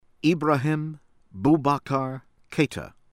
HAMANI, AHMED MOHAMED AG AH-mehd    muh-HAH-mehd   ahg   hah-MAH-nee